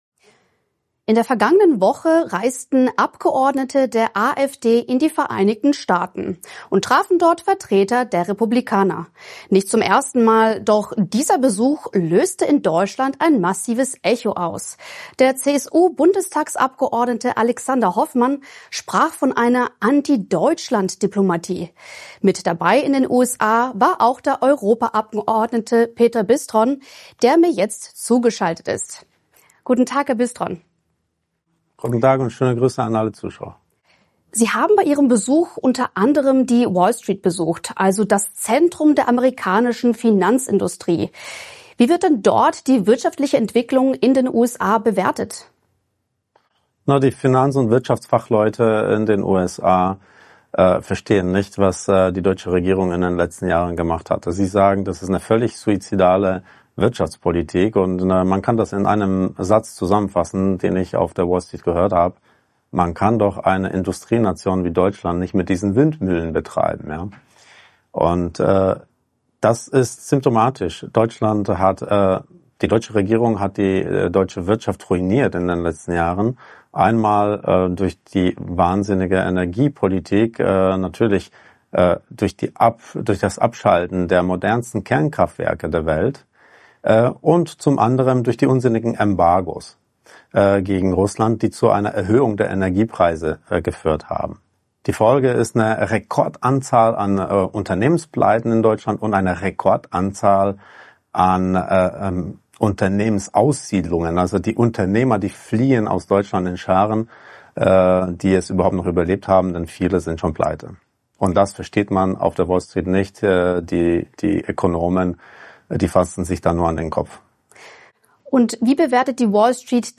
Im Interview mit AUF1 gibt er einen